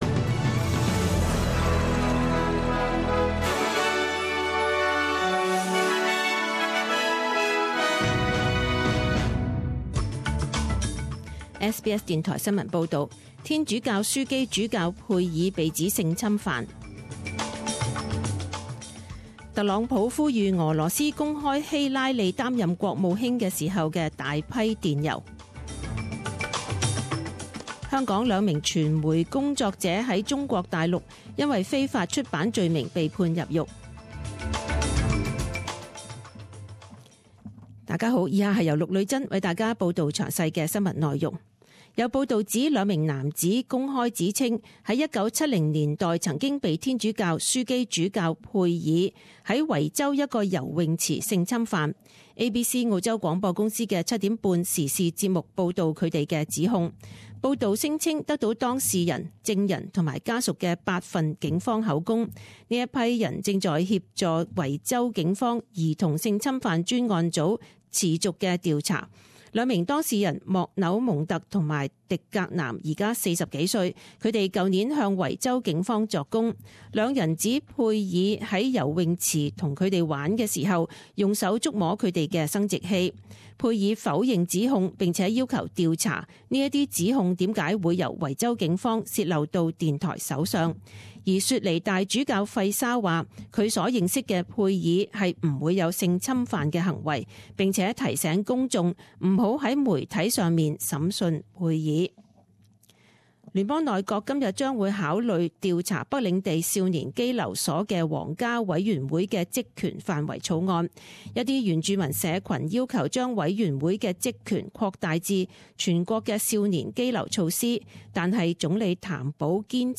十点钟新闻报导（七月二十八日）